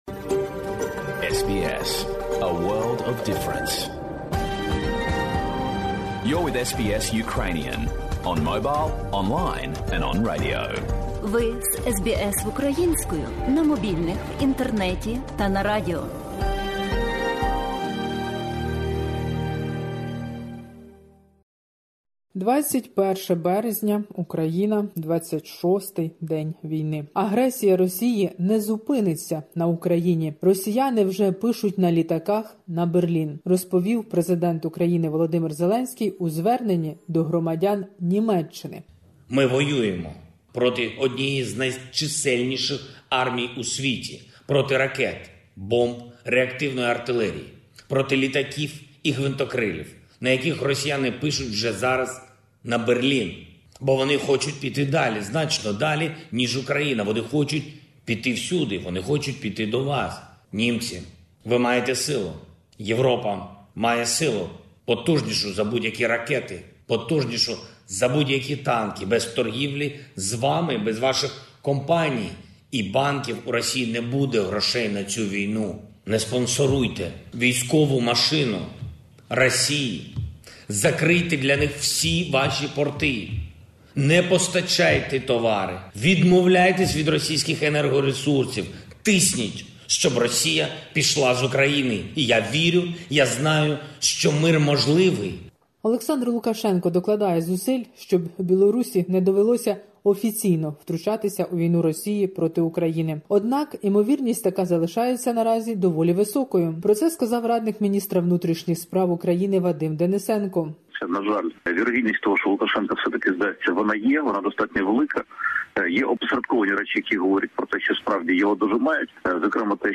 Добірка новин. 26-ий день війни.